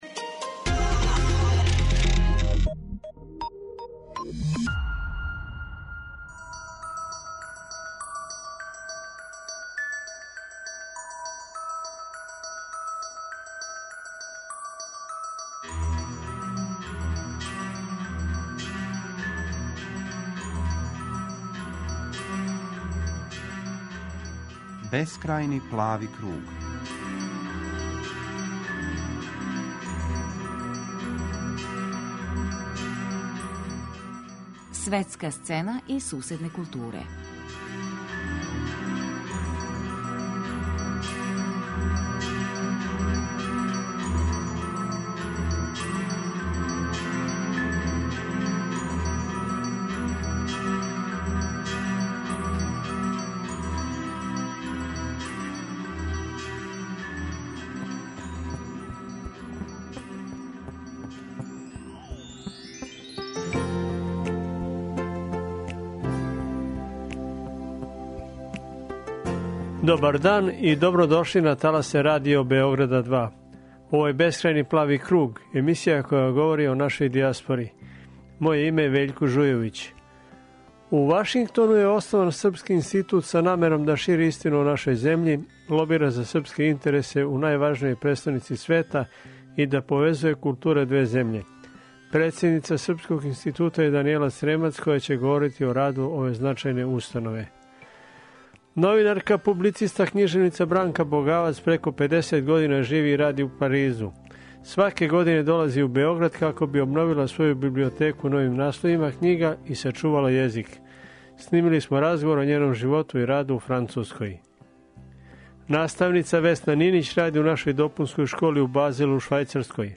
Снимили смо разговор о њеном животу и раду у Француској.